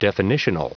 Prononciation du mot definitional en anglais (fichier audio)
Prononciation du mot : definitional